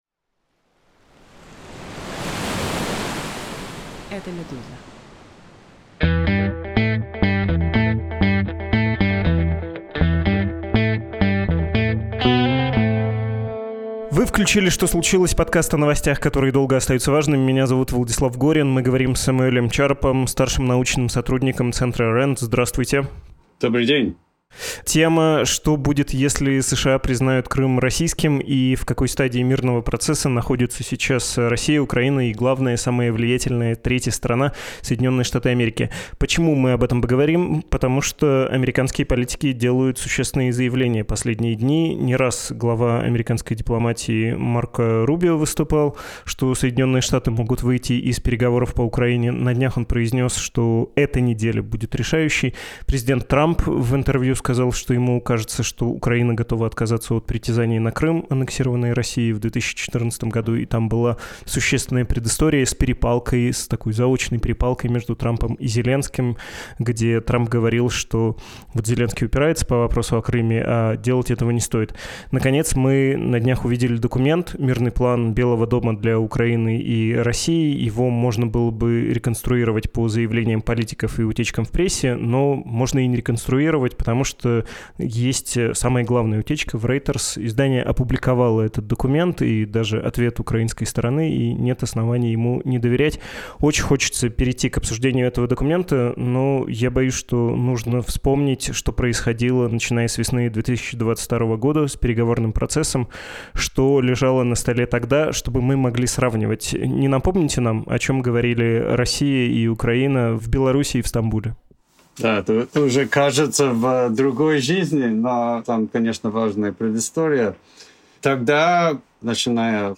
Говорим о том, как устроена самая близкая к государству бизнес-империя, которую возглавляет Ковальчук и члены его семьи, — с журналистом-расследователем